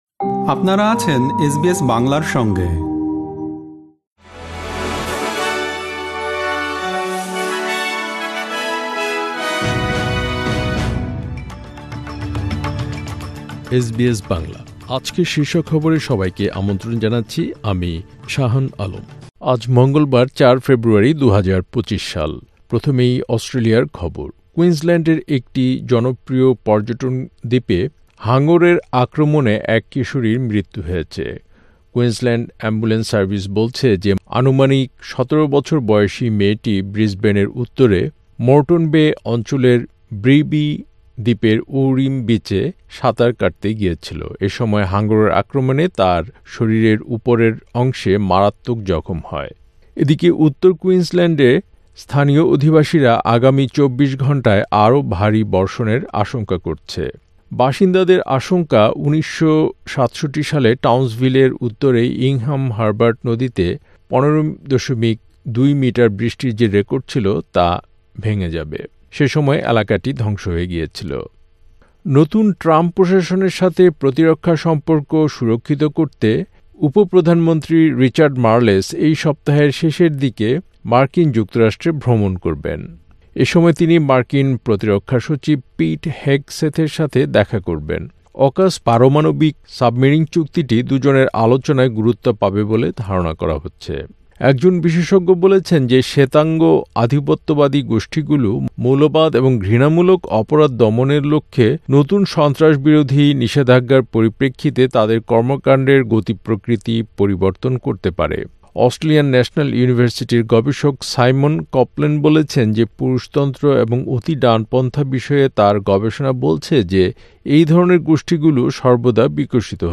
এসবিএস বাংলা শীর্ষ খবর: ৪ ফেব্রুয়ারি, ২০২৫